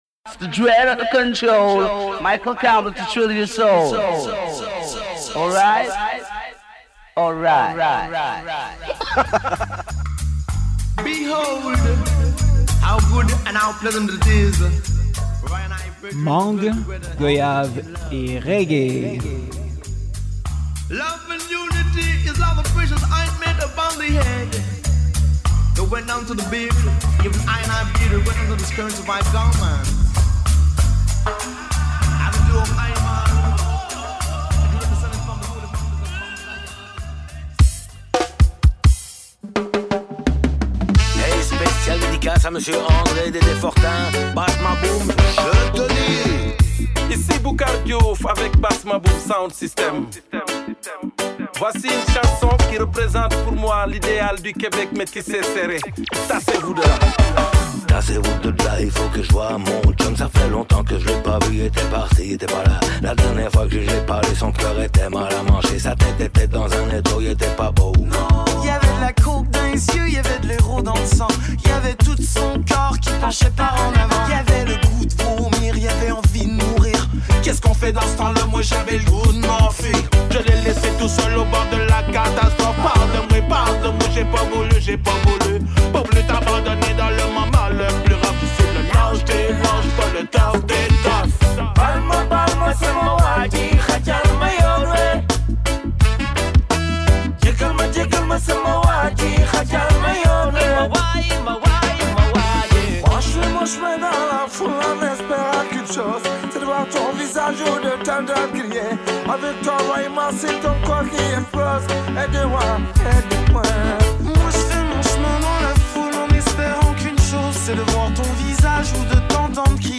Au programme : 100% de reggae franco-québécois.